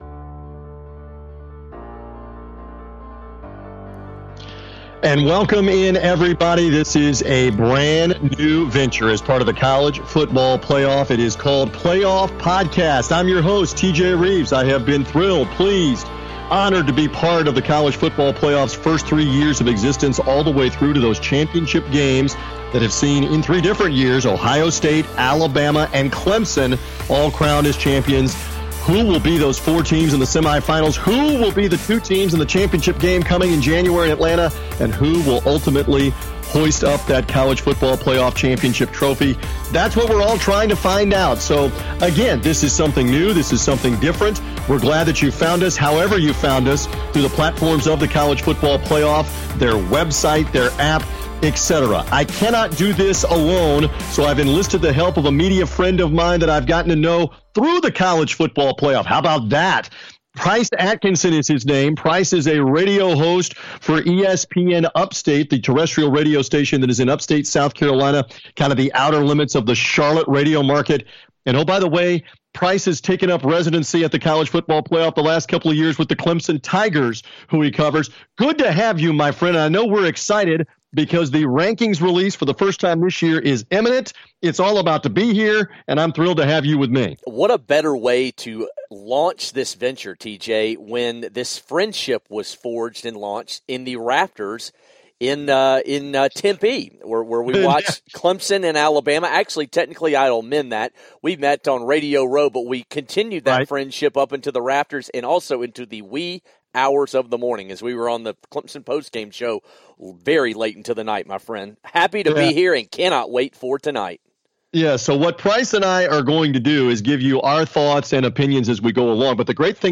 featuring exclusive interviews